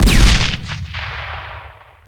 smallcannon.ogg